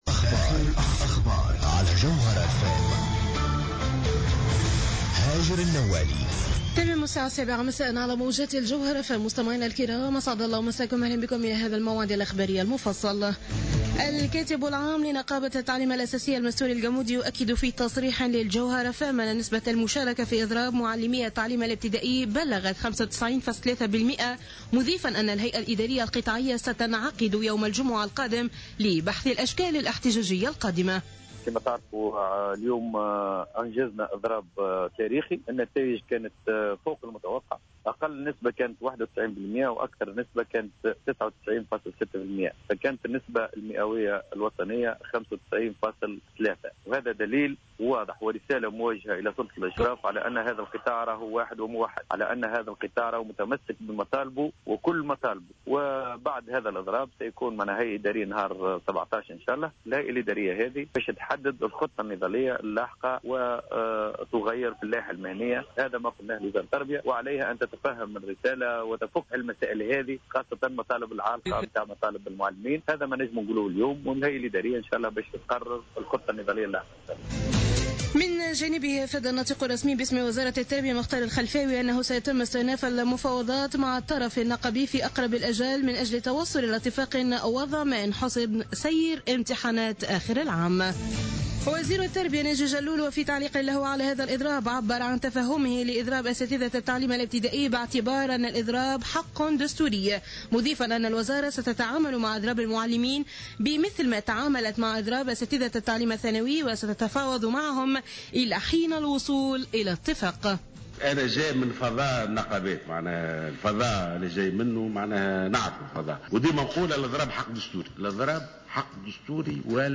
نشرة أخبار السابعة مساء ليوم الأربعاء 15 أفريل 2015